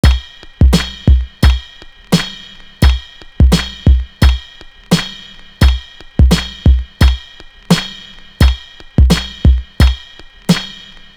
Professional Drum.wav